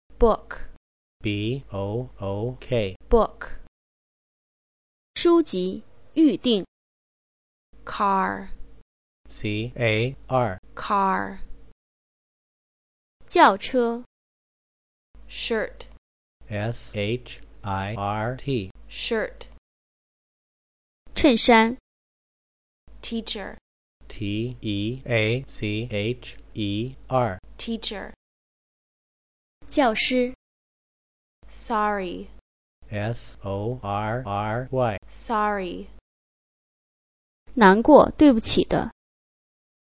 老版本里的单词发音全部废弃不用，聘请美国外教重新录制单词发音，清晰真人美音使背单词成为一种享受！
用《单词随身宝》制作的单词MP3样例
6. 增加中文解释真人发音。